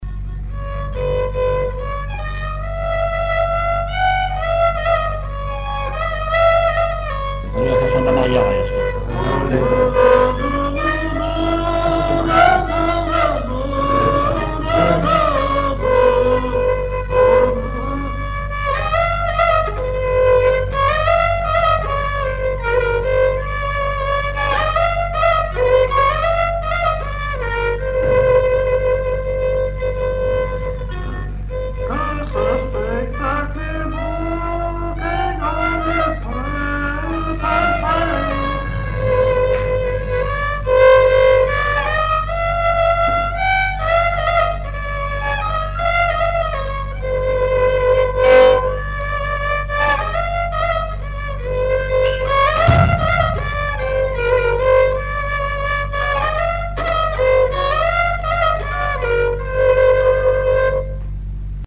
circonstance : fiançaille, noce
Genre strophique
chansons populaires et instrumentaux
Pièce musicale inédite